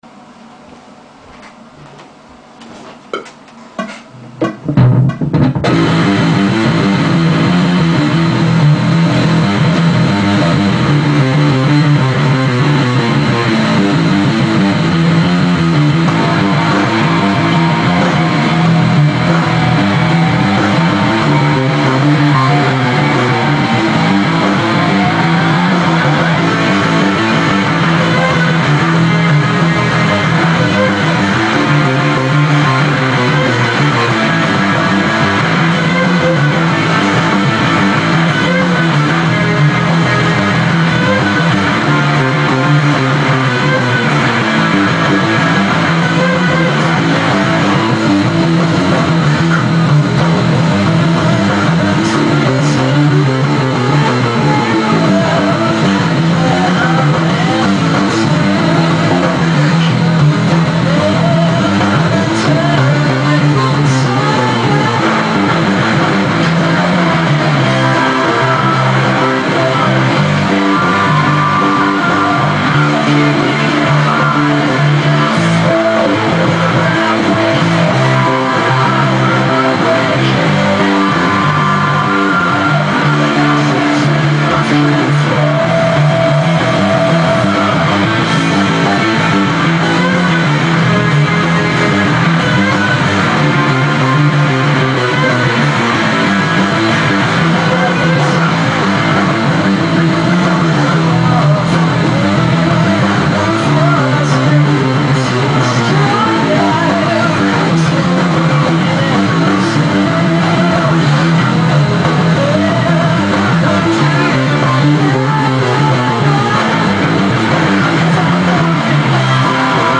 Music / Rock
playing bass